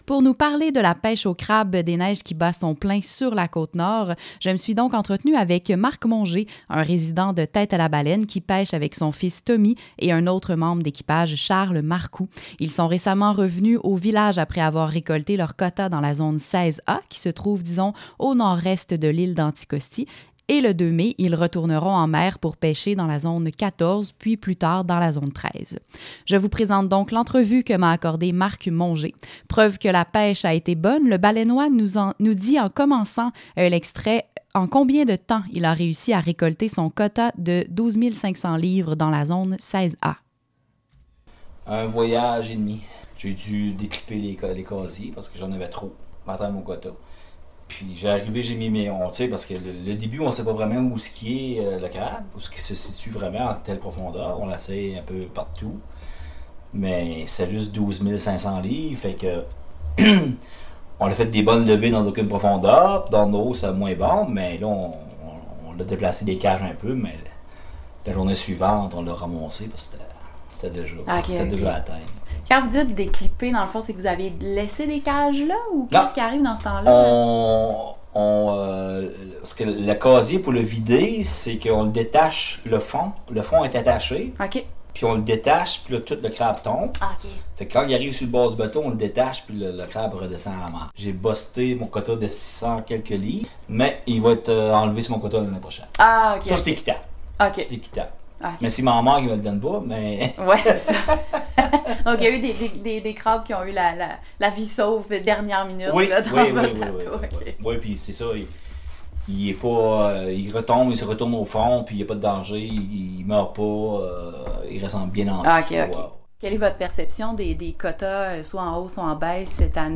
Pêche-au-crabe-entrevue.mp3